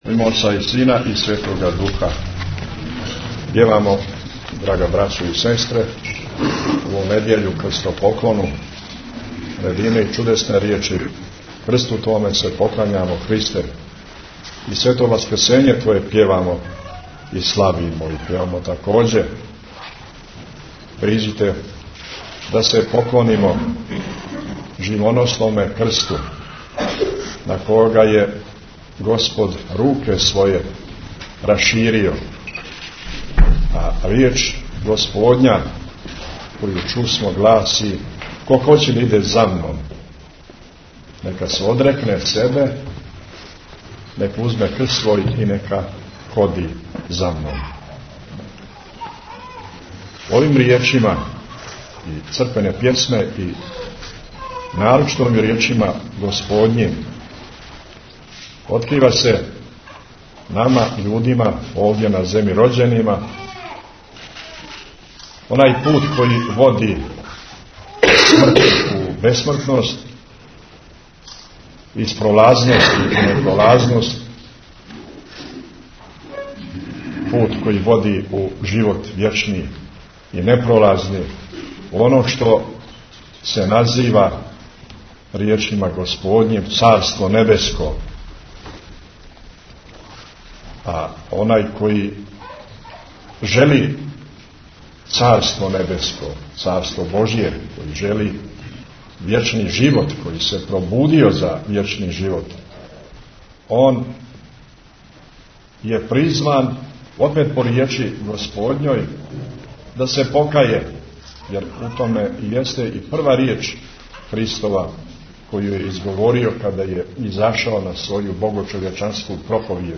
Преузмите аудио датотеку 424 преузимања 68 слушања Митрополит Амфилохије на Крстопоклону недјељу служио у Острогу Tagged: Бесједе 18:55 минута (3.25 МБ) Бесједа Његовог Високопреосвештенства Архиепископа Цетињског Митрополита Црногорско - приморског Г. Амфилохија са Свете Архијерејске Литургије коју је на Крстопоклону недјељу Часног поста, у недјељу 23. марта 2014. године служио у манастиру Острог.